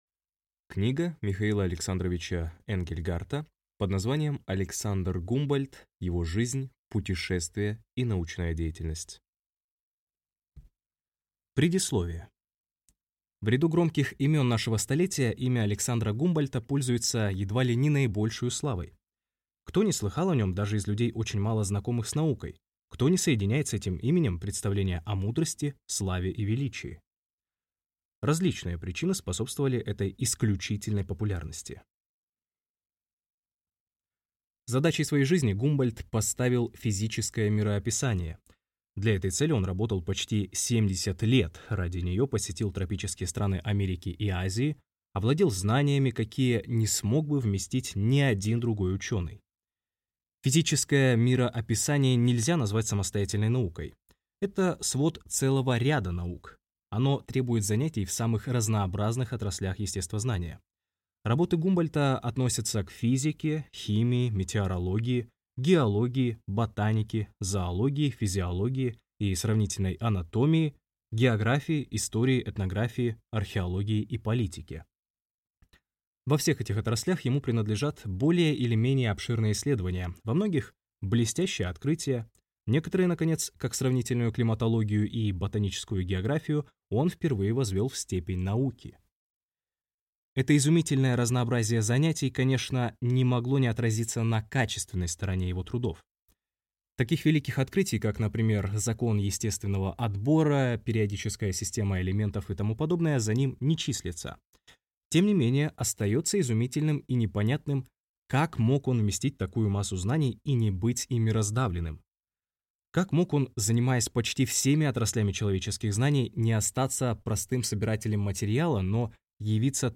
Аудиокнига Александр Гумбольдт. Его жизнь, путешествия и научная деятельность | Библиотека аудиокниг